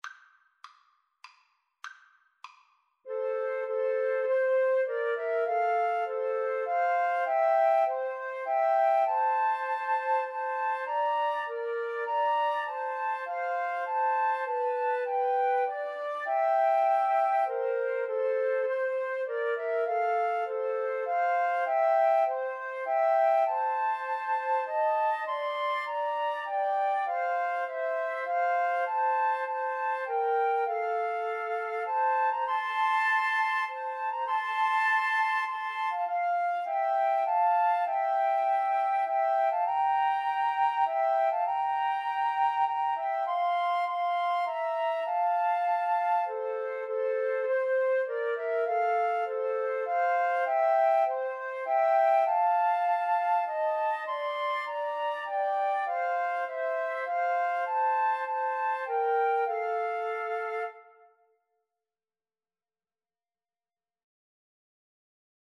Flute 1Flute 2Flute 3
3/4 (View more 3/4 Music)
Traditional (View more Traditional Flute Trio Music)